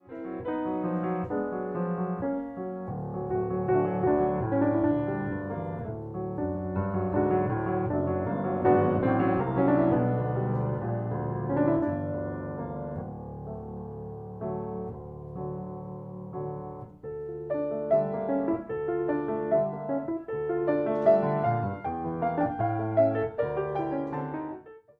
To give a sense of what well-preserved analogue audio can sound like once it has been captured digitally, here is a short excerpt from a piano performance of Brahms's Rhapsody in B minor, Op. 79 No. 1.
Excerpted and fade-adjusted from the original on Wikimedia Commons, used under CC BY-SA 4.0. This excerpt is likewise distributed under CC BY-SA 4.0.